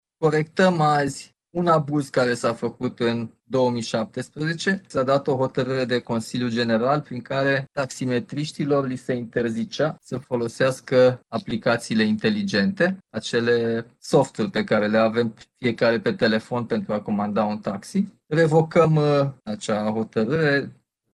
Exista chiar și un punct de vedere al Curţii de Justiţie a Uniunii Europene pe acest subiect, spune primarul general, Nicușor Dan: